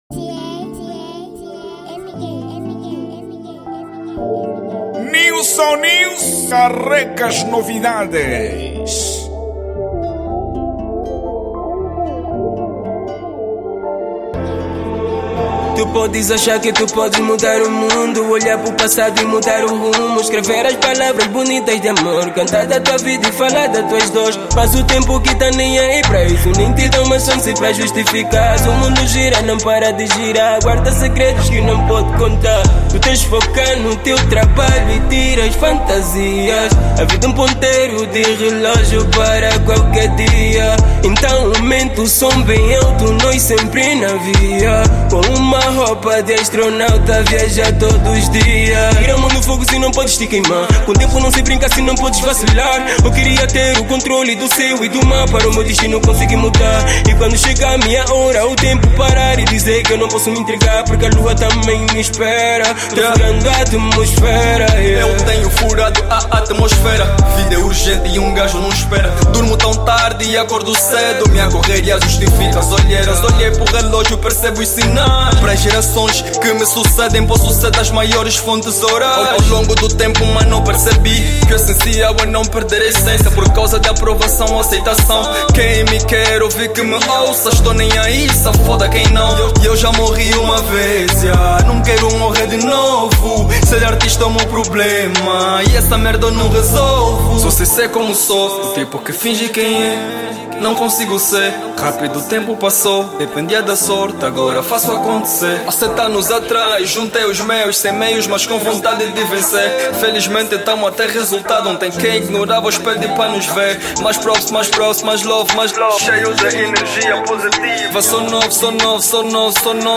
| Hip-Hop